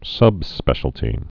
(sŭbspĕshəl-tē)